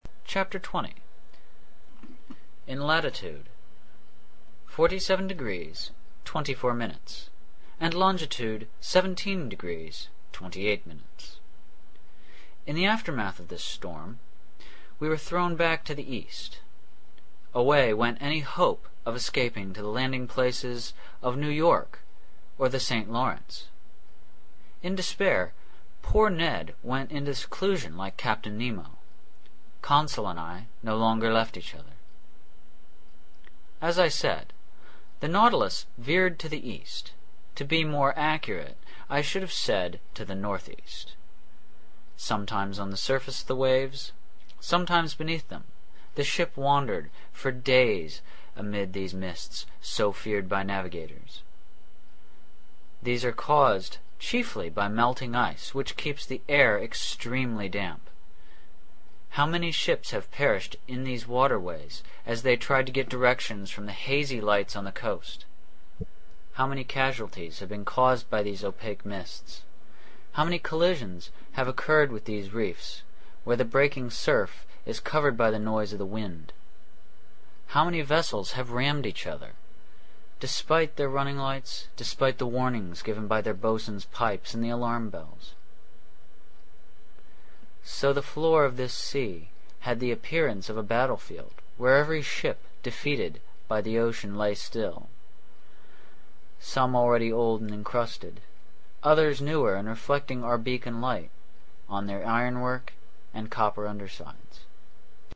英语听书《海底两万里》第526期 第33章 北纬47.24度, 西经17.28度(1) 听力文件下载—在线英语听力室
在线英语听力室英语听书《海底两万里》第526期 第33章 北纬47.24度, 西经17.28度(1)的听力文件下载,《海底两万里》中英双语有声读物附MP3下载